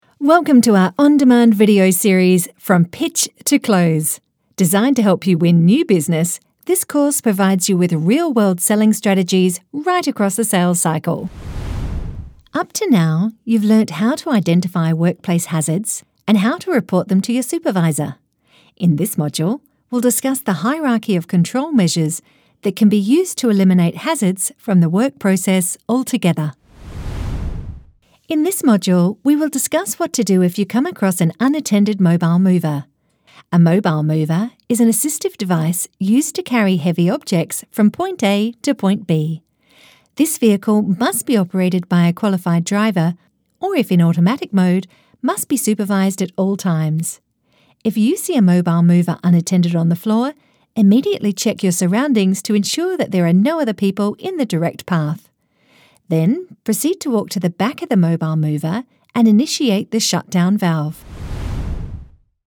Female
English (Australian)
I'm described as natural and friendly and booked by studios looking for young & perky, a mum, energetic, smooth or seductive.
E-Learning
Home Studio Samples
0725Elearning_raw_file.mp3